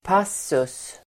Ladda ner uttalet
Uttal: [p'as:us]